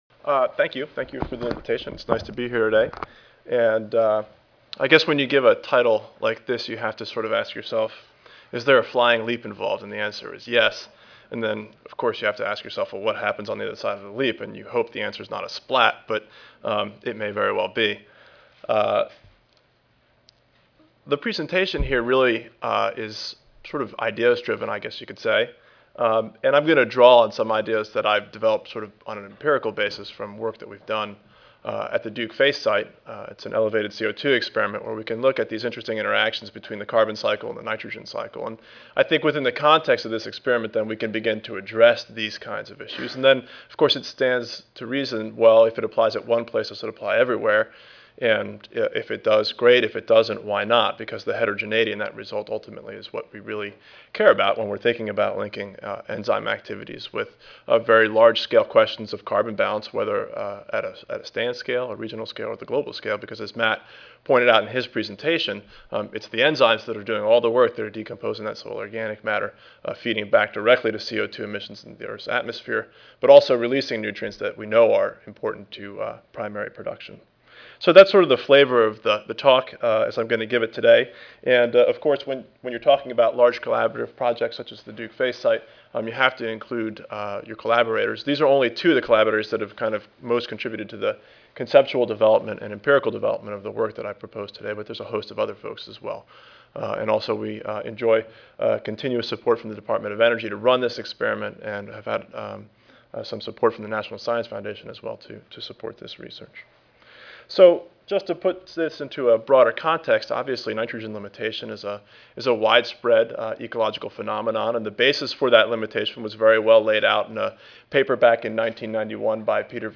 Session: Symposium--Linking Soil Enzyme Activities to Ecosystem Functions: I (2009 Annual Meeting (November 1-5, 2009))
Audio File Recorded presentation